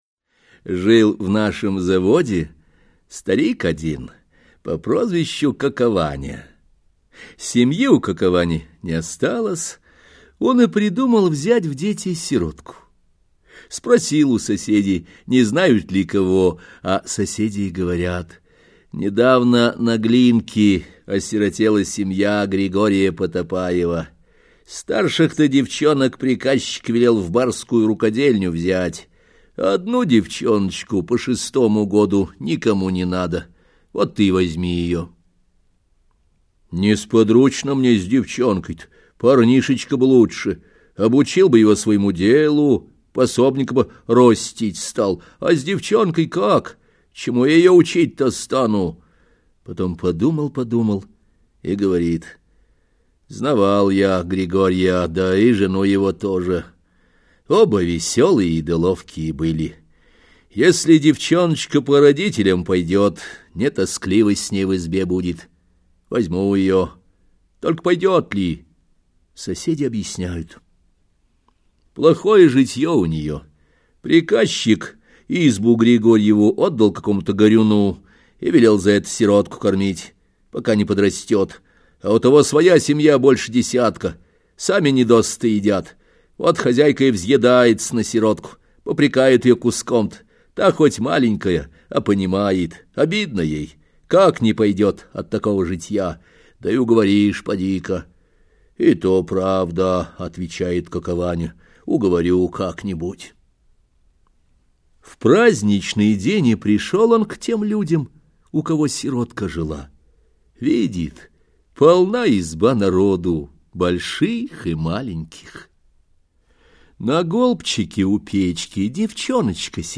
Аудиокнига Каменный цветок, Малахитовая шкатулка и другие сказы | Библиотека аудиокниг